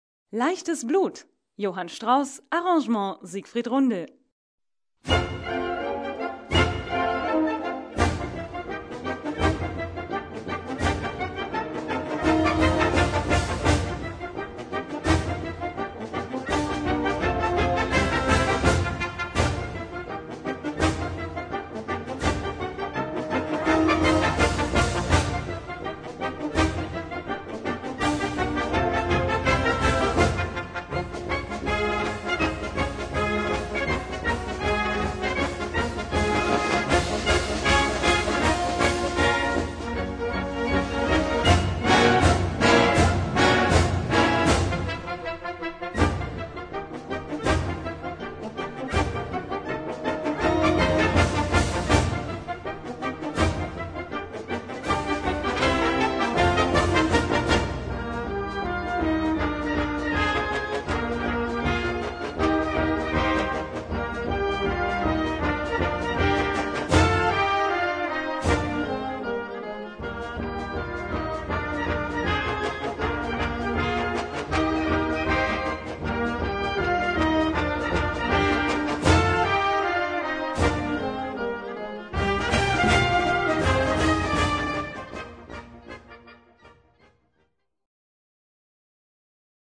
Gattung: Polka schnell
Besetzung: Blasorchester